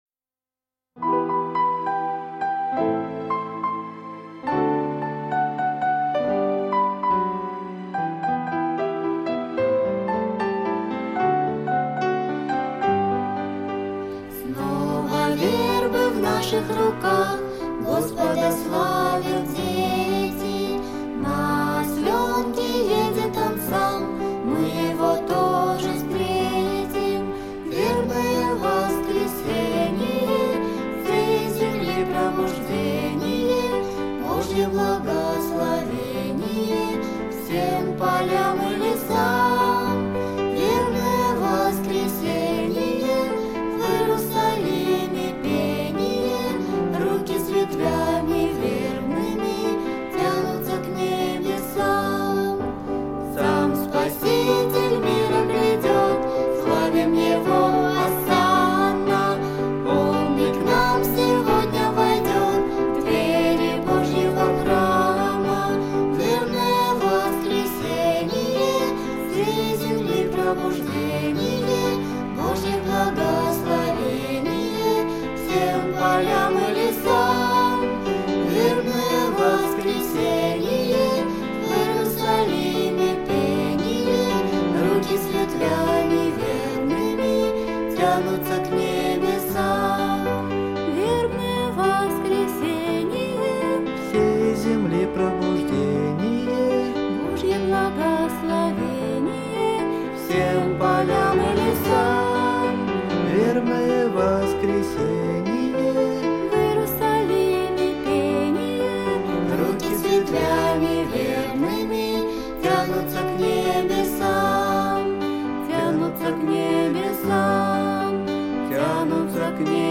Детская песня
Христианские Песни ⛪